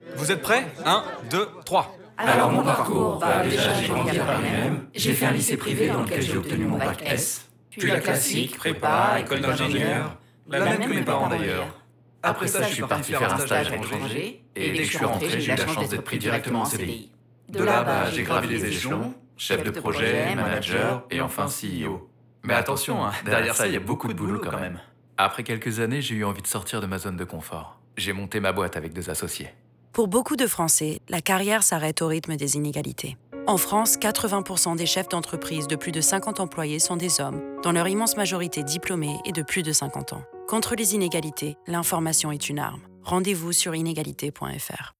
Grâce à l’écriture de trois spots radio d’un genre totalement inédit : chacun raconte une histoire portée par les voix de 10 comédiens représentatifs de la société française.
Le travail du son, orchestré par le studio O’Bahamas, a fait l’objet d’une attention toute particulière pour faire ressortir les caractéristiques de chacune des voix même lors des choeurs.
Spot radio 2